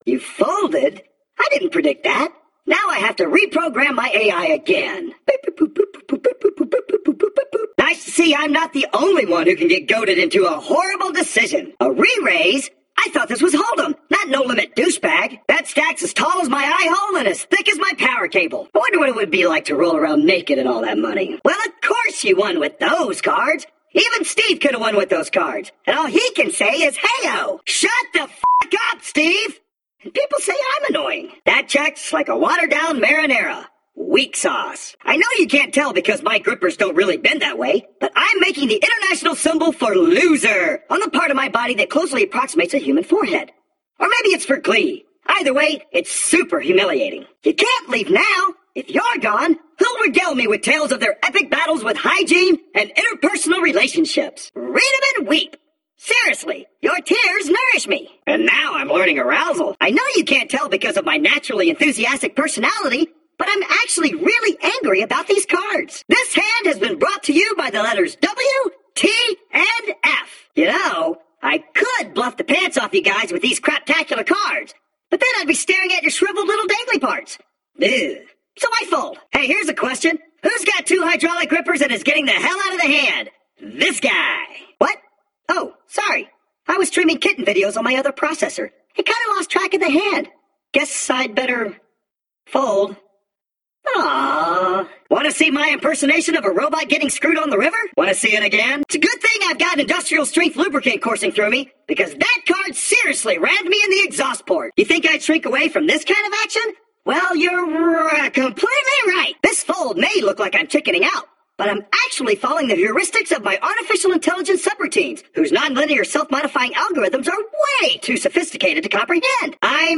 headcanon lil hal voice: claptrap from borderlands